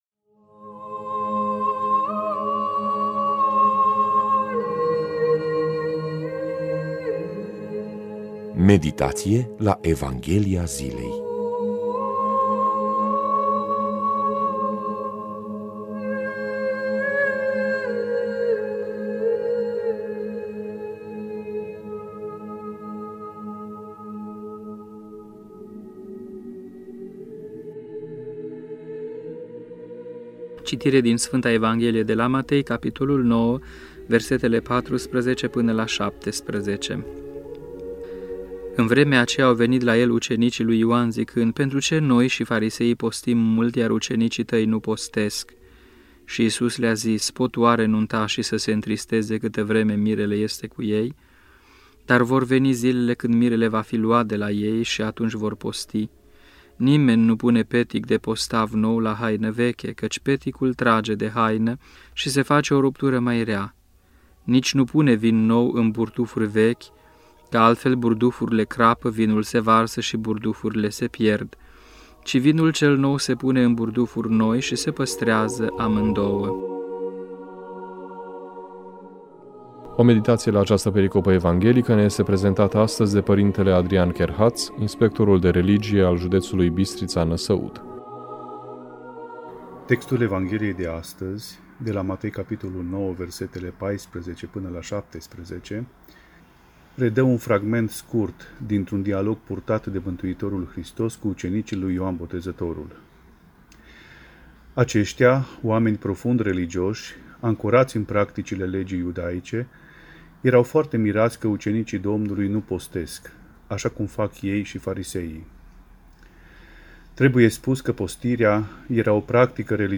Meditație la Evanghelia zilei